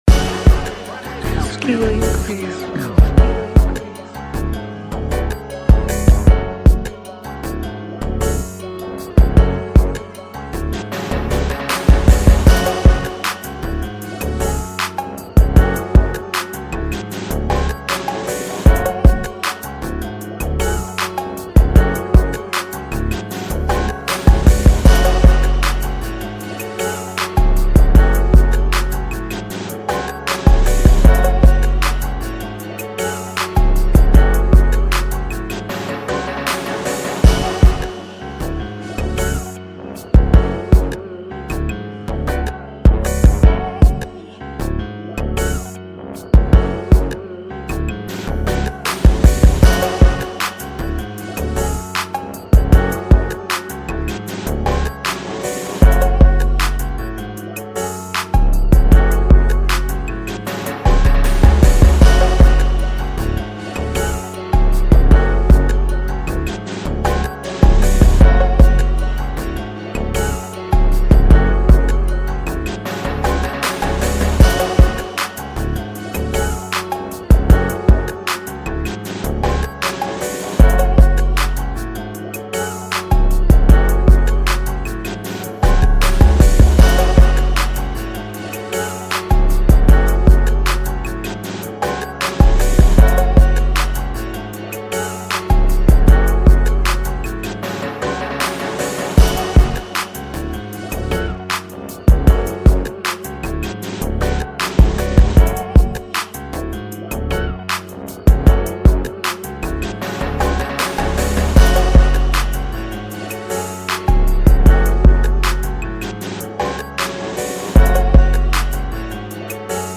2024 in Hip-Hop Instrumentals